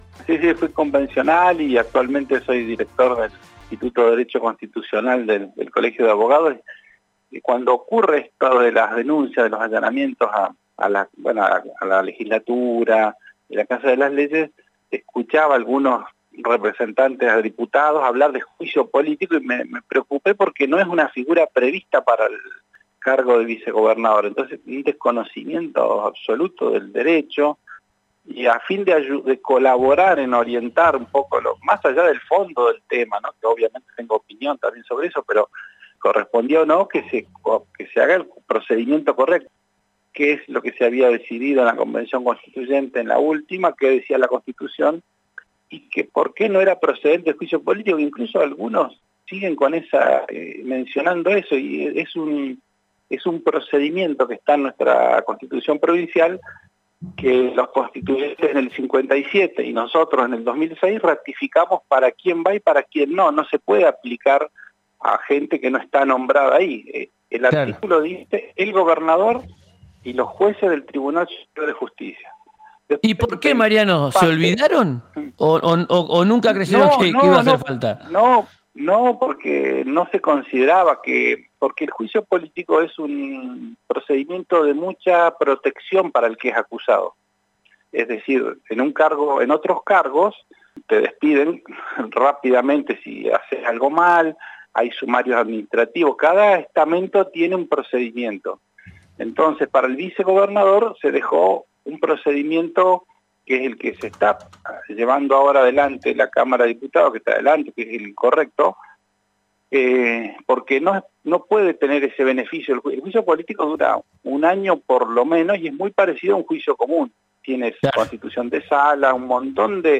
» Es un error político tremendo», afirmó en diálogo con «Ya es tiempo»